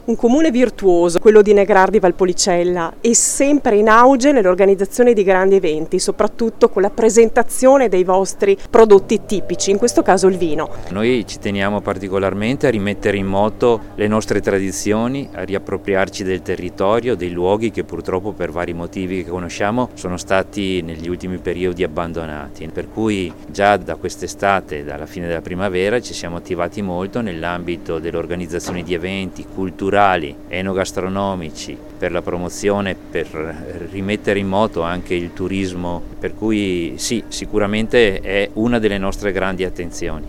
Soddisfazione per il nuovo appuntamento è stata espressa anche dal sindaco di Negrar, Roberto Grison: